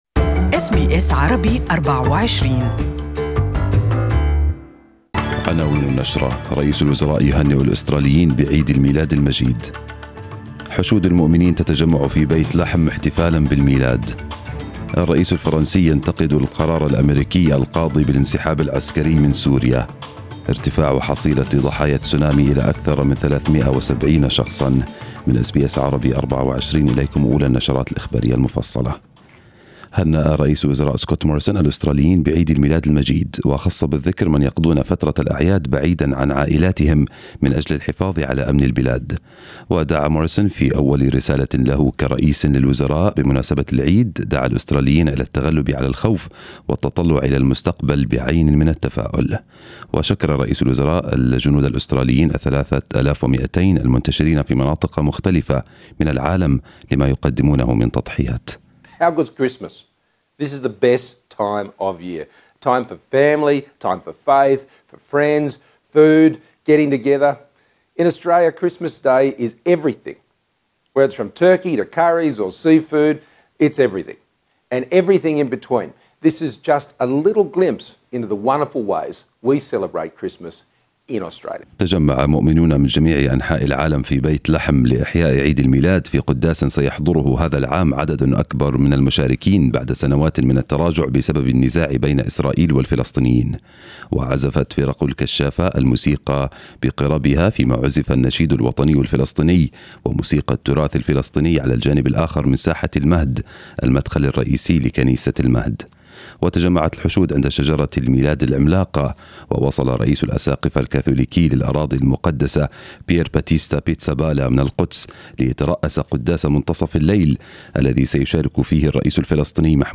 Morning news bulletin in Arabic.